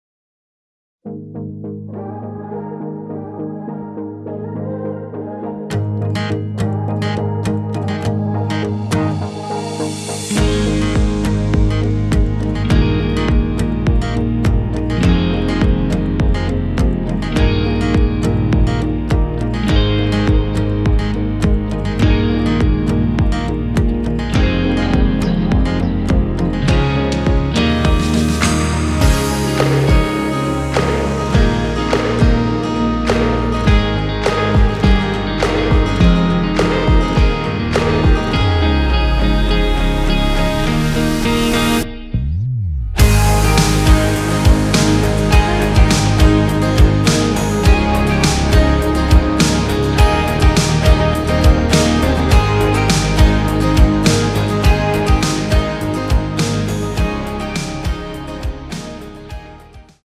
Ab
앞부분30초, 뒷부분30초씩 편집해서 올려 드리고 있습니다.